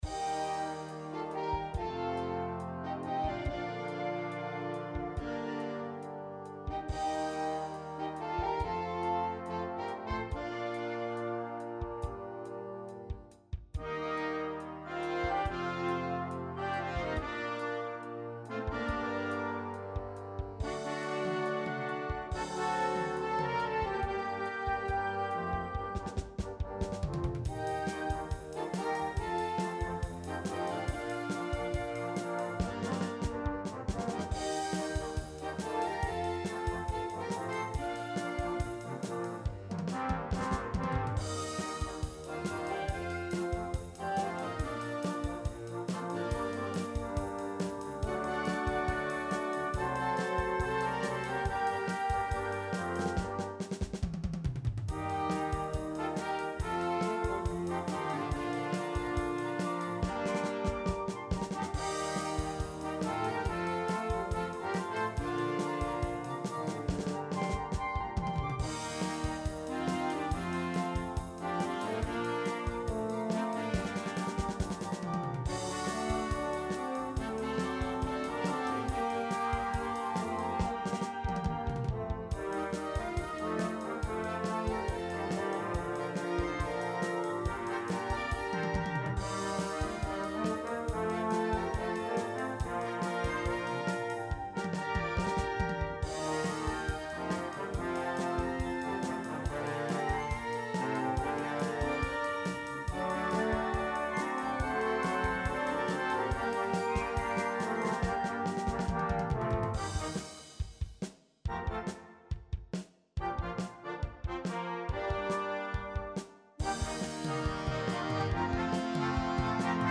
Arrangement
Type de formation : Fanfare / Harmonie / Banda
Pré-écoute non téléchargeable · qualité réduite